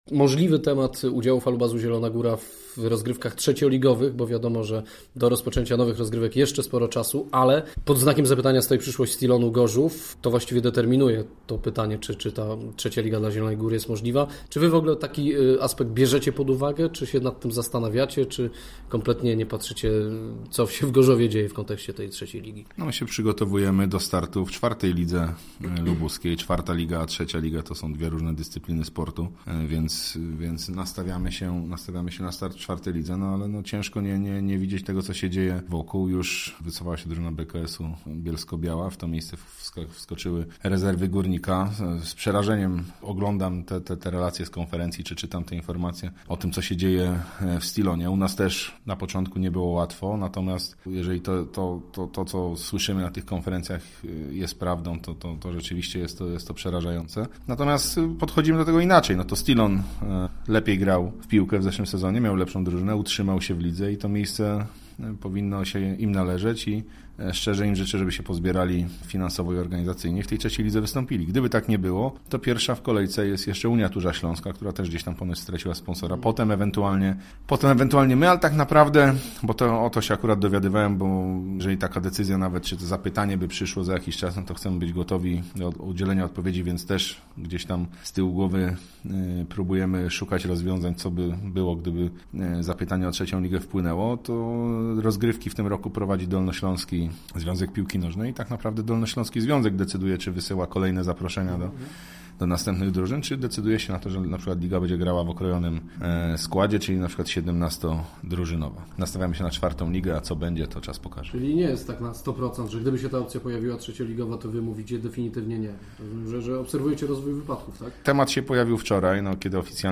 Poniżej rozmowa